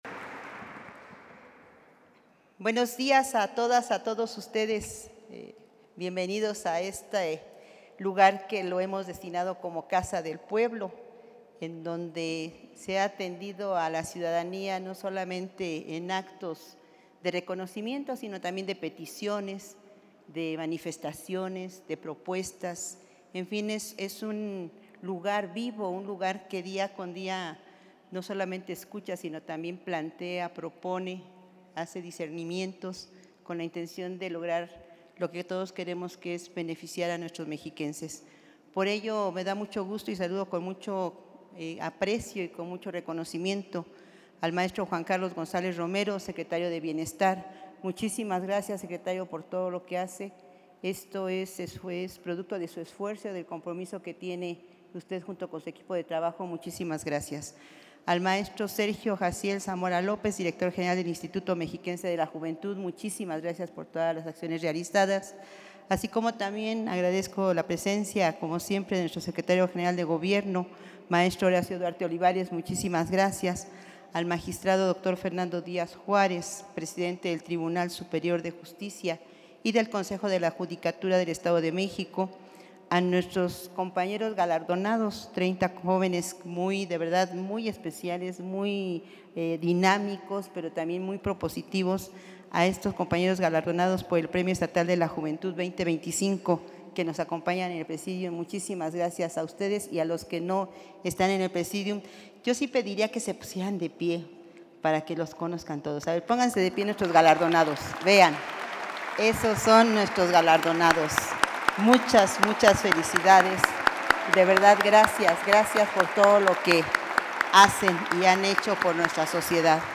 AUDIO_MENSAJE-COMPLETO_DGA_Entrega-del-Premio-Estatal-de-la-Juventud-2025.mp3